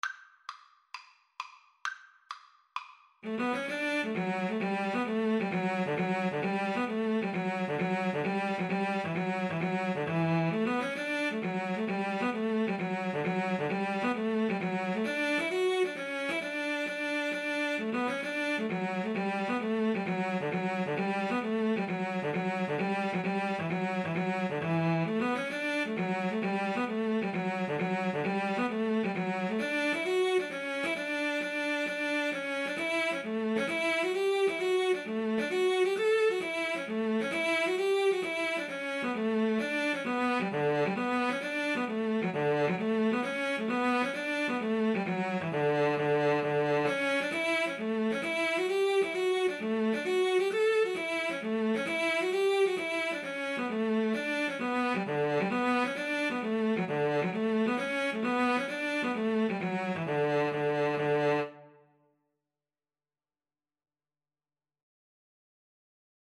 D major (Sounding Pitch) (View more D major Music for Cello Duet )
=132 Molto allegro
Traditional (View more Traditional Cello Duet Music)